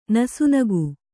♪ nasu nagu